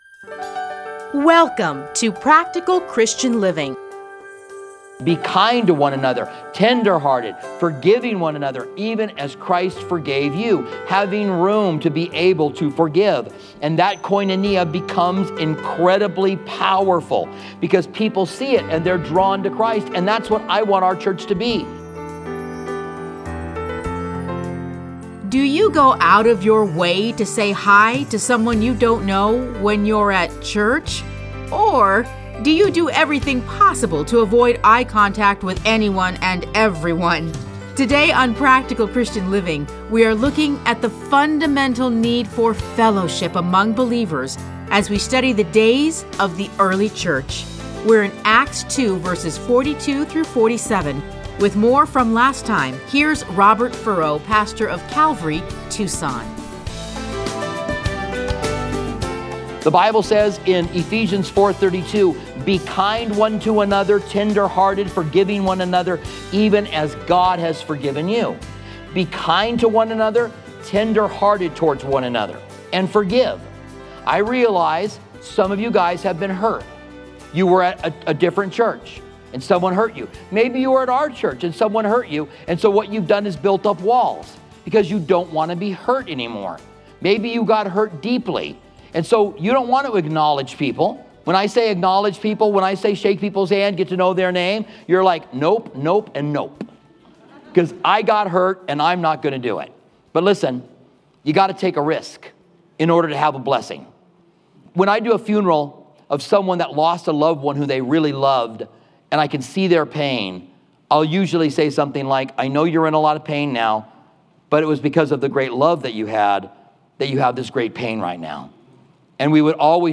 Listen to a teaching from Acts 2:42-47.